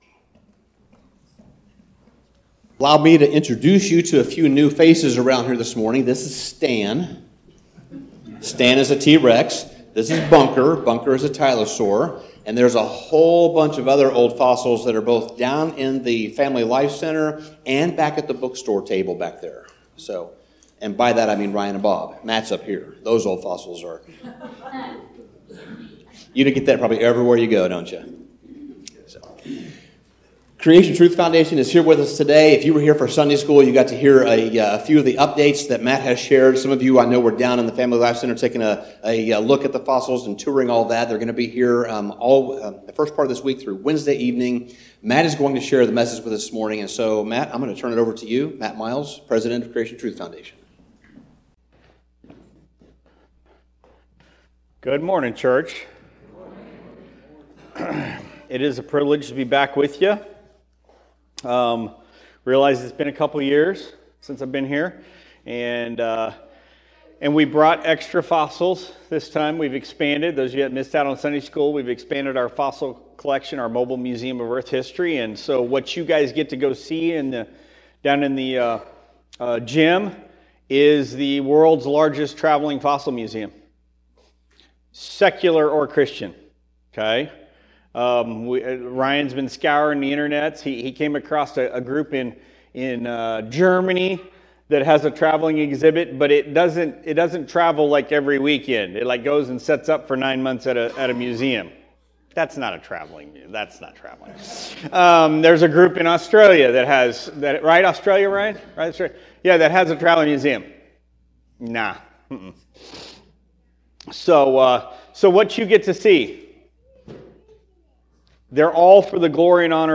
Sermon Summary